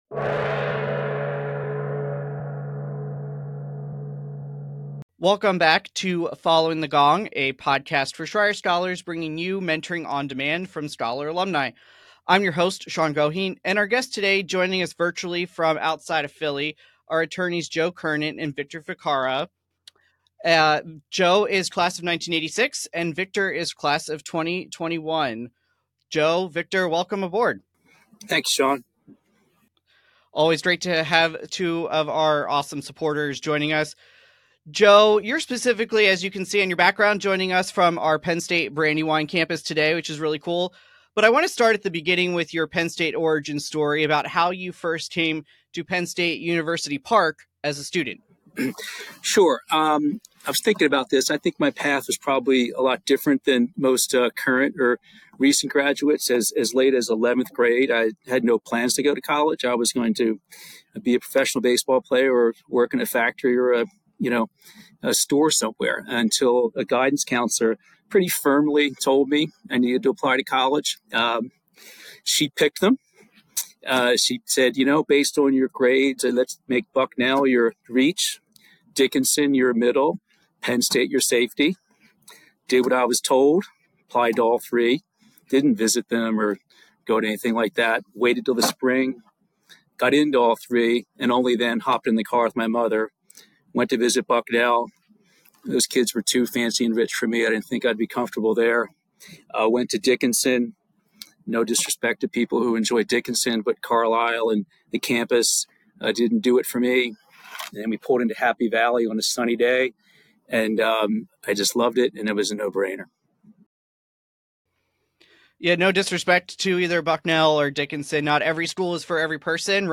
They discuss the importance of mentorship, the value of extracurricular involvement, and the impact of their honors thesis experiences on their professional paths. The conversation also covers practical advice for current students, including networking strategies, time management, and the evolving role of AI in law and accounting.